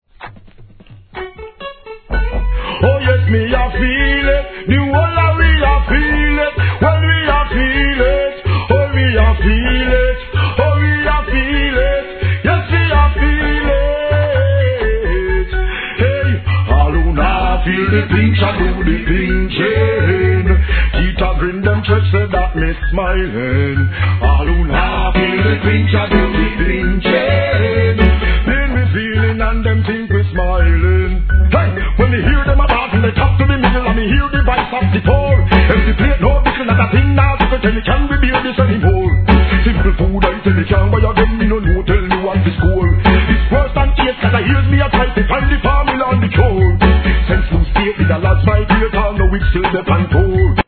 REGGAE
マイナー調のRHYTHMで高速フロウ！！